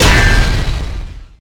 bladeslice5.ogg